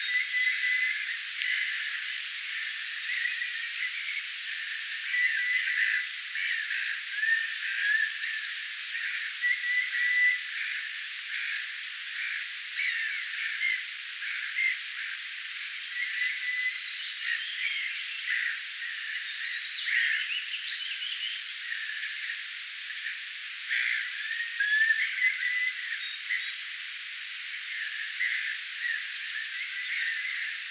kun ne ääntelevät rannalla levätessäänkin
kuovit_muuttoaantelevat_seisoessaan_rannalla.mp3